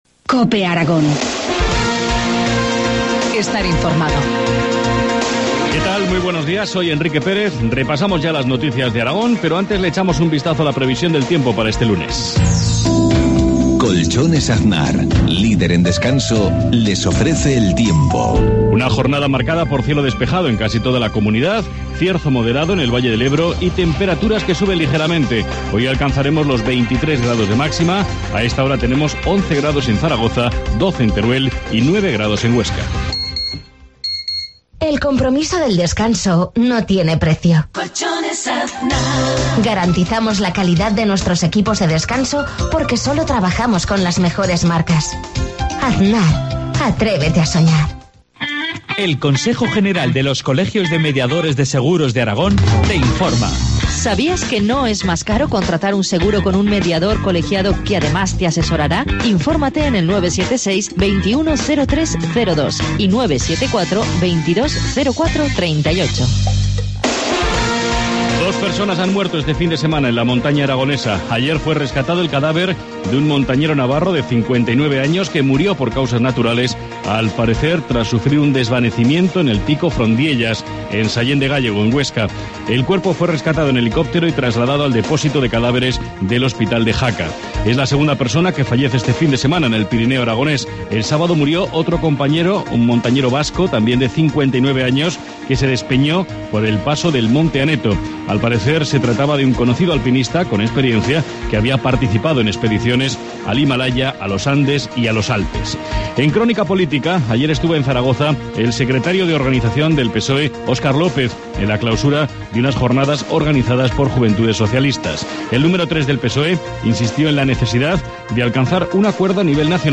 Informativo matinal, lunes 13 de mayo, 7.53 horas